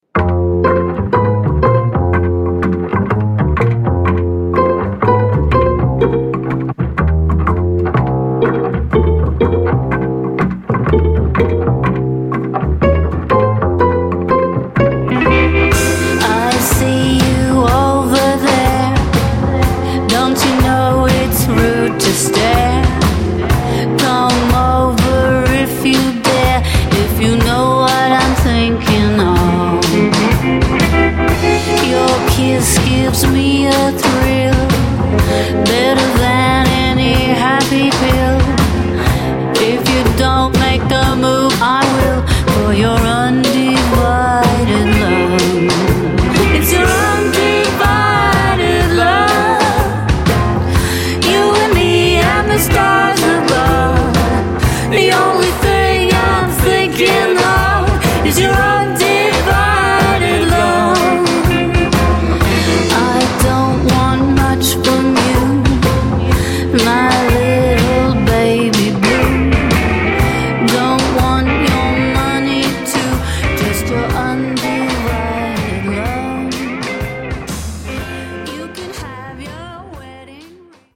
You might call the music retro sounding .